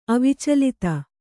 ♪ avicalita